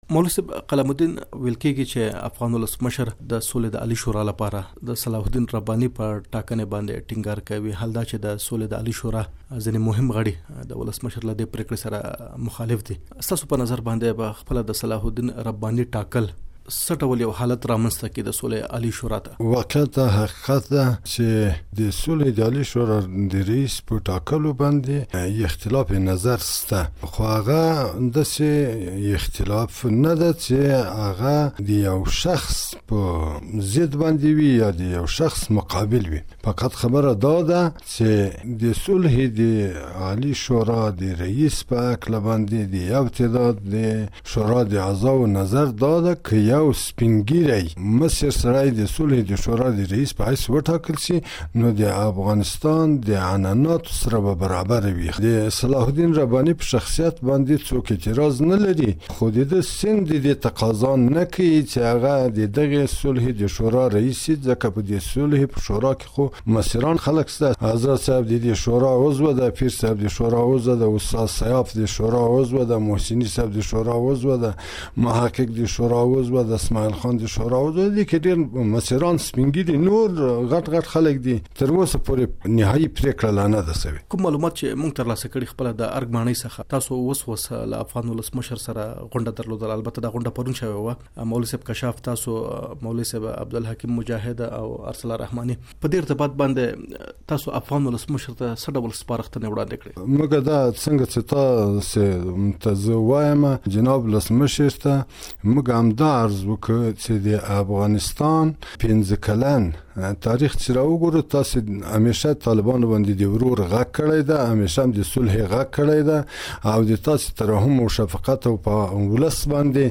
مرکه
د سولې د عالي شورا د مشر د ټاکلو په اړه له مولوي قلم الدین سره مرکه